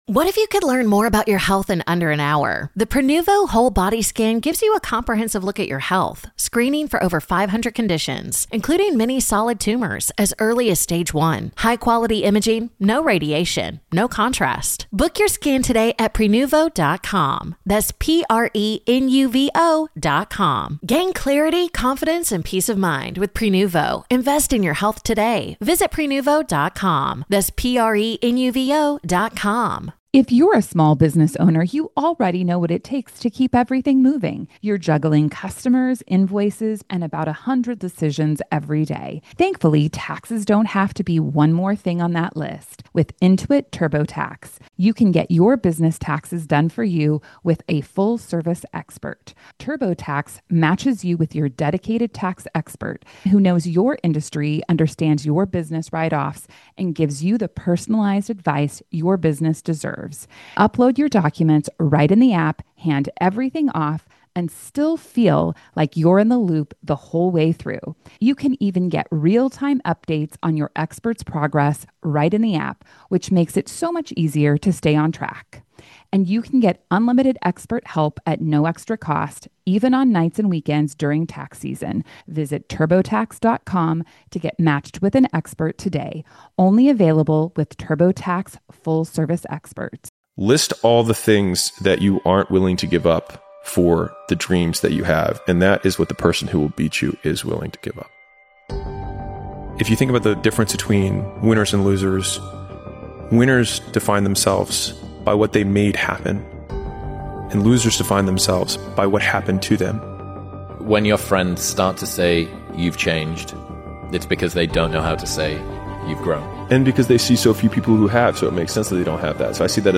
Alex Hormozi - The art of starting motivational speech